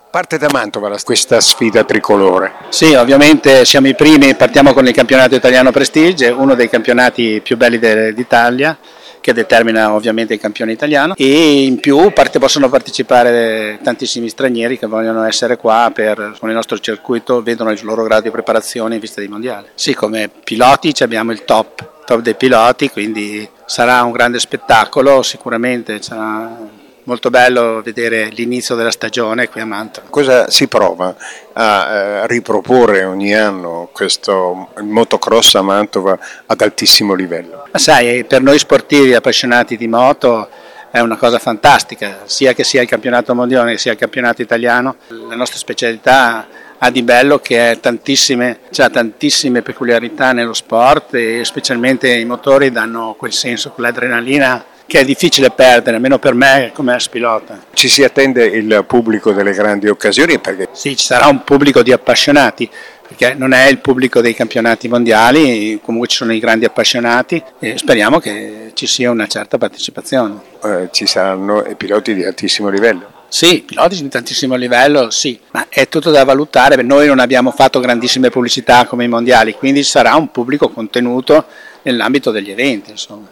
raccogliendo le dichiarazioni degli attori principali: